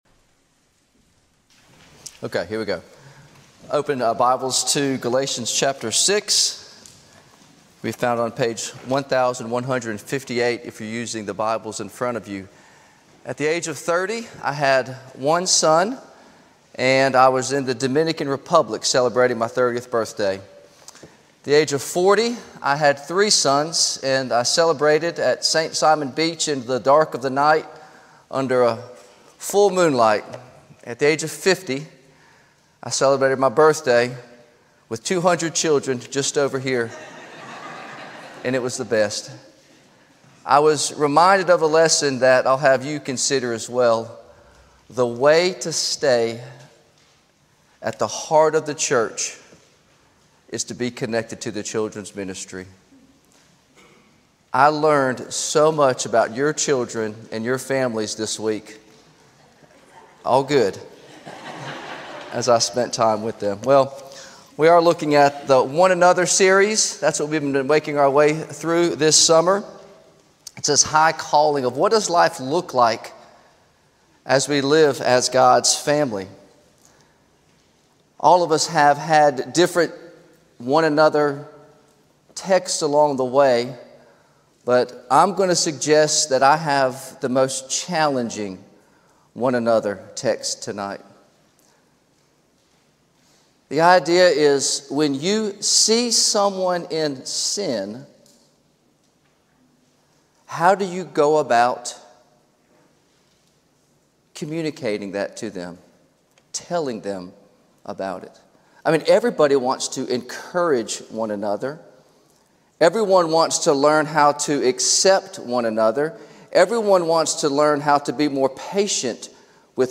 Sermons - First Presbyterian Church of Augusta